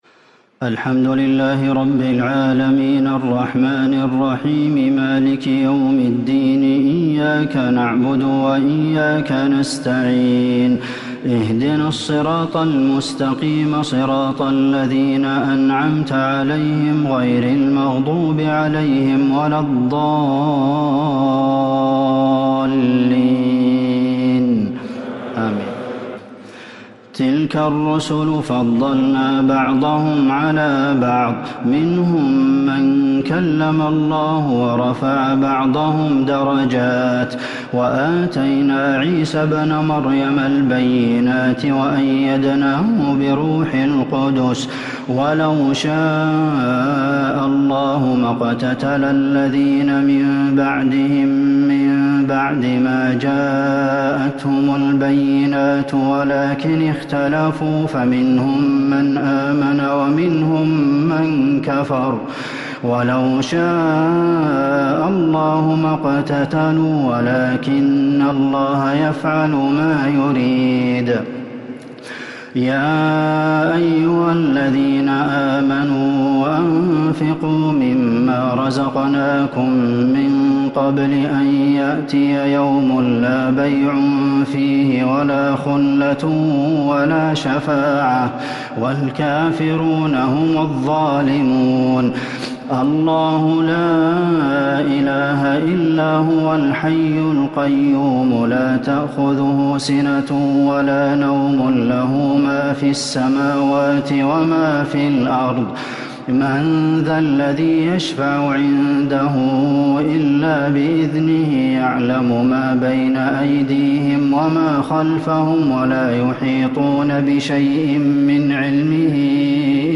تراويح ليلة 3 رمضان 1444هـ من سورة البقرة (253-271) | Taraweeh 3st night Ramadan 1444H > تراويح الحرم النبوي عام 1444 🕌 > التراويح - تلاوات الحرمين